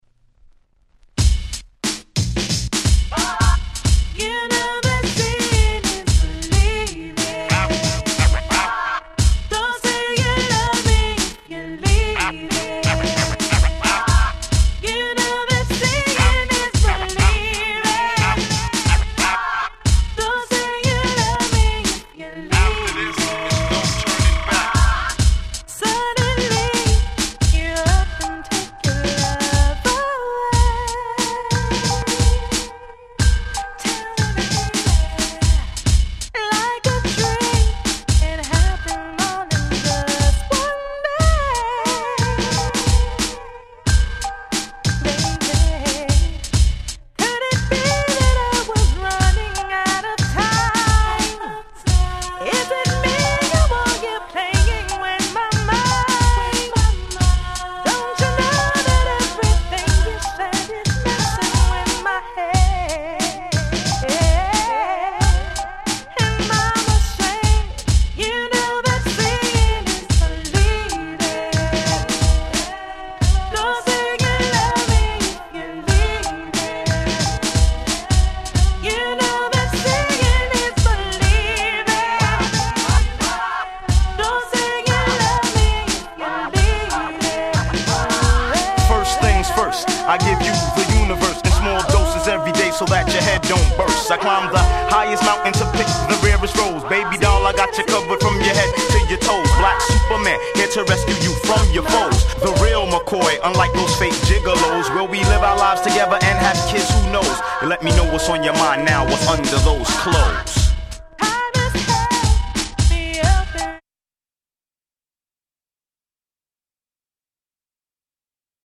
Nice Hip Hop Soul♪
Originalとはまた違った使い方の出来るNeo Soul的に格好良いRemixってことですな。